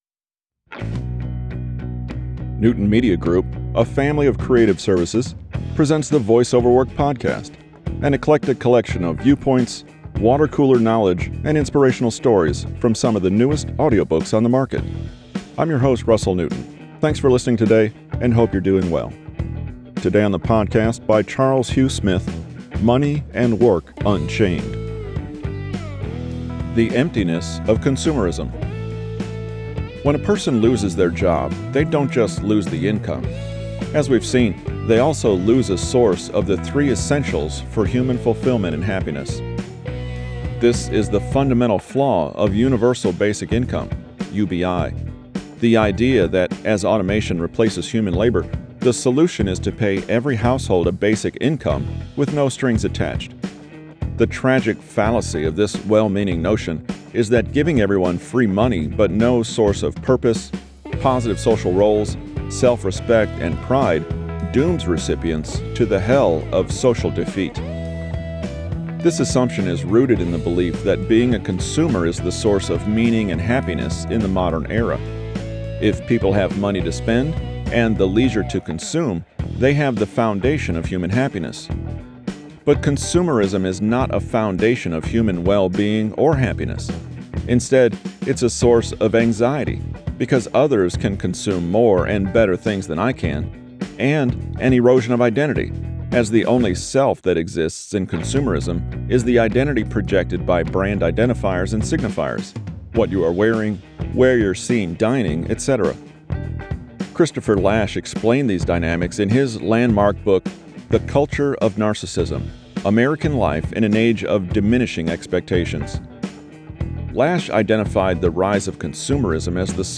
Charles Hugh Smith from his recent book Money and Work Unchained, available now as an audiobook.